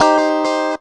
GTR 97 CM.wav